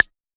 Index of /musicradar/retro-drum-machine-samples/Drums Hits/Tape Path A
RDM_TapeA_MT40-Clave.wav